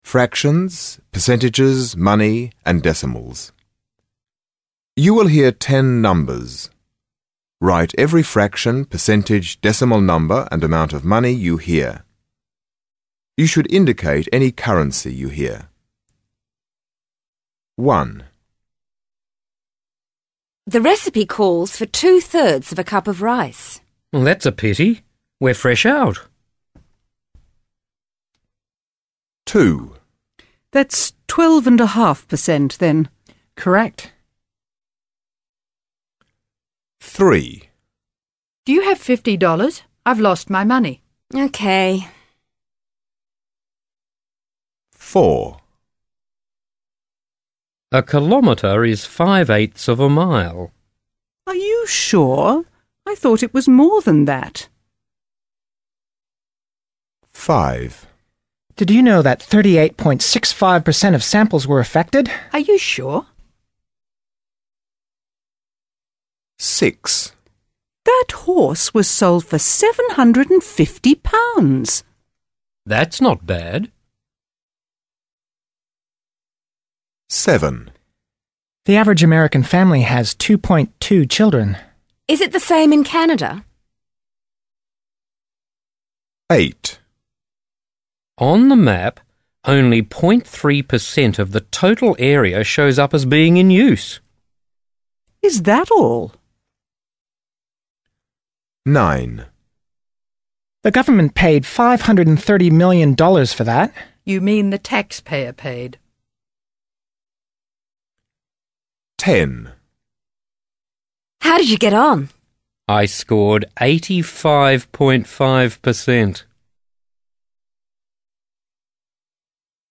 You will hear ten amounts in ten conversations.